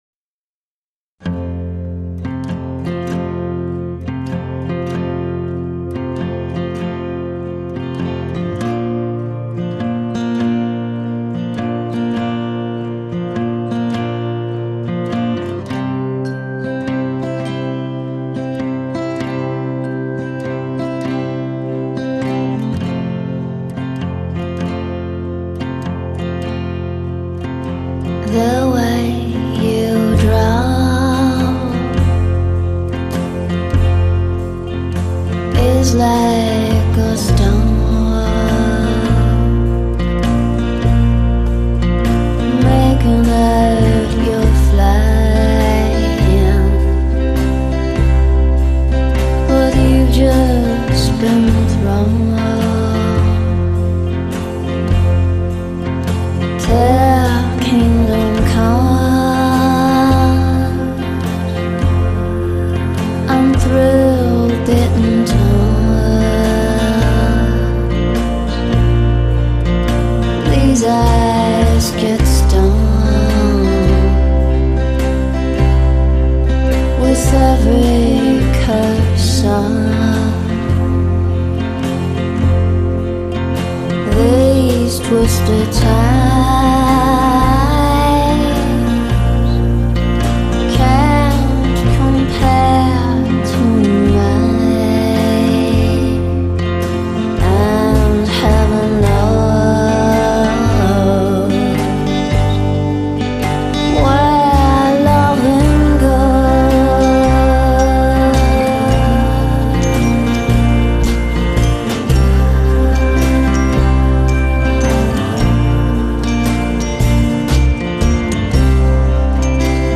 迷人的白色噪音底子隐没成布帘点缀的星光
有着清淡着色的回转，笼罩起薄纱般透明微亮的清晨，一点一点逐渐消失在呼吸的尾梢。